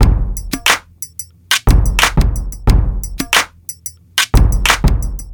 • 90 Bpm Old School Drum Loop Sample G Key.wav
Free drum groove - kick tuned to the G note. Loudest frequency: 1800Hz
90-bpm-old-school-drum-loop-sample-g-key-OZo.wav